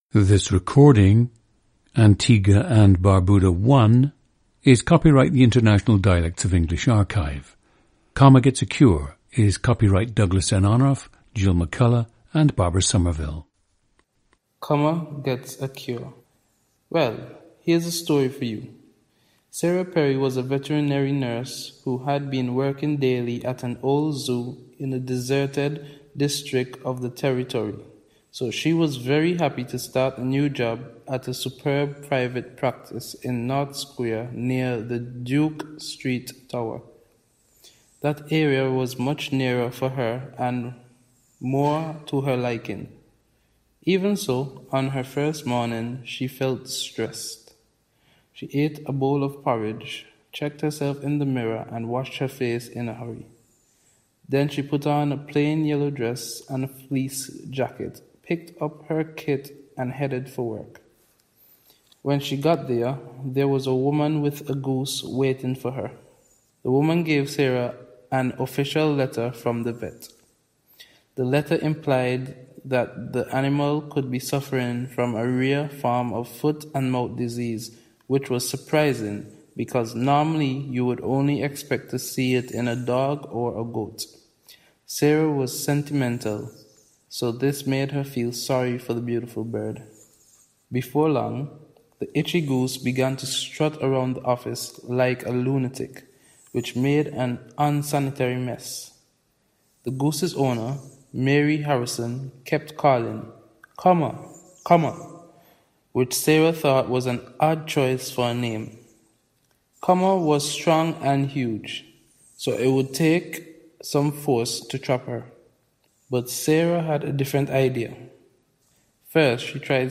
PLACE OF BIRTH: Antigua
GENDER: male
ETHNICITY: Afro-Caribbean
Having spent many years working in the tourism industry, the subject feels that his natural accent has become a more standardized Caribbean English (known by Caribbean residents as Standard English). He tried to speak in a natural voice during the interview, but, as the interviewee is not native to the region, he found it difficult to speak in his full natural dialect.